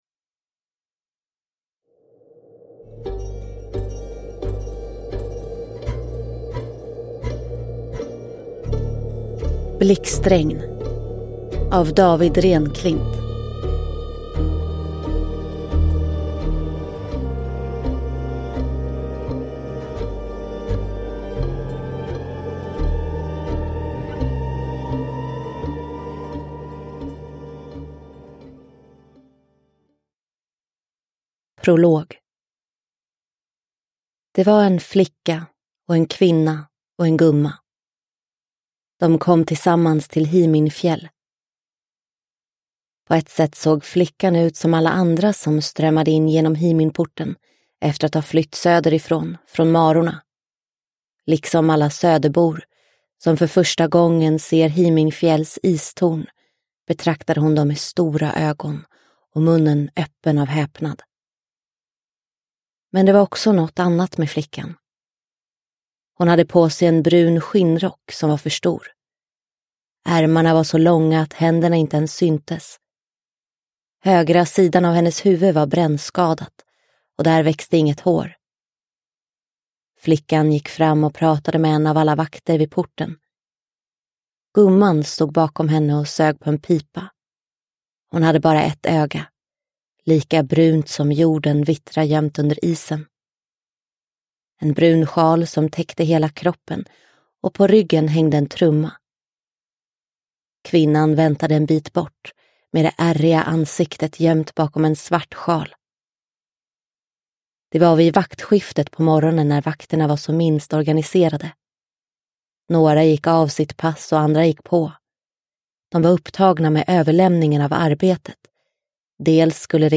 Blixtregn – Ljudbok – Laddas ner